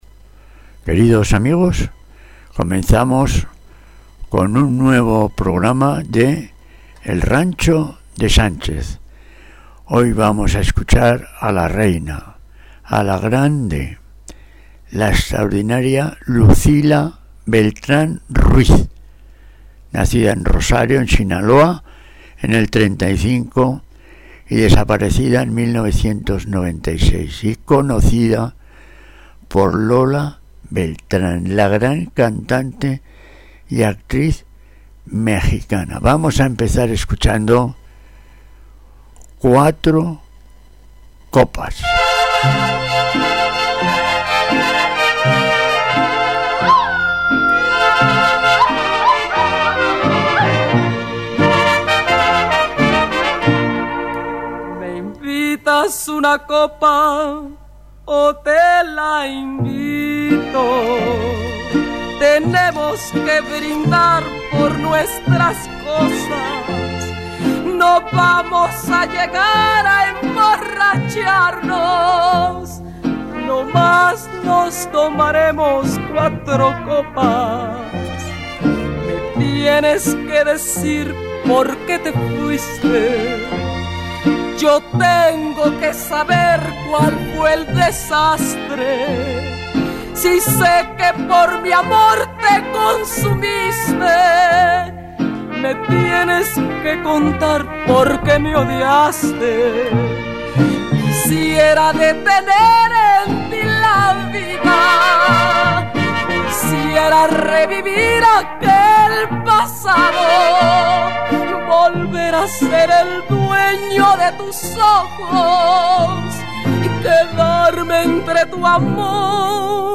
Canción Ranchera